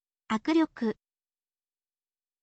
akuryoku